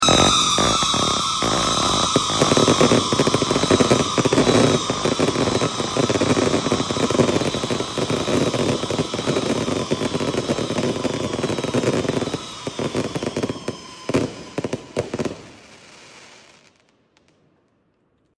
Trend viral of Wolf sound sound effects free download
Trend viral of Wolf sound firework!🎆🎇